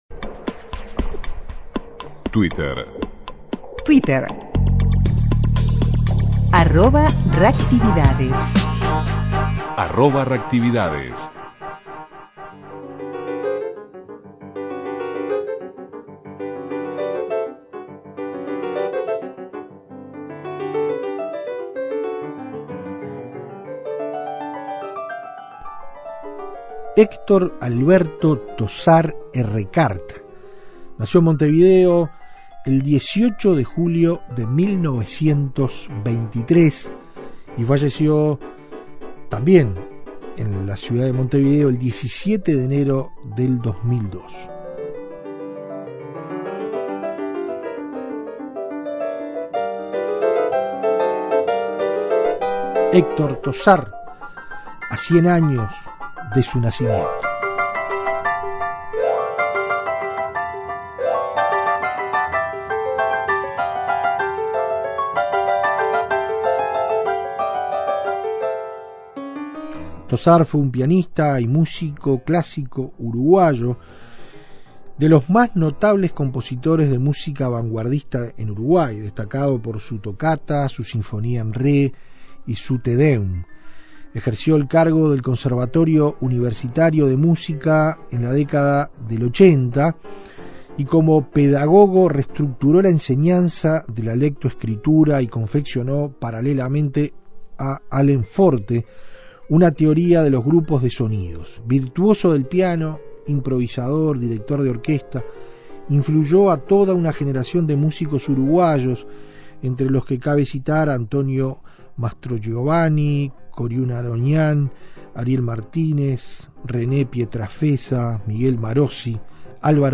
Luis Alberto de Herrera, discursos, jingles, la radio, a 150 años de su nacimiento.